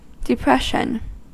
Ääntäminen
US
IPA : /dɪˈpɹɛʃən/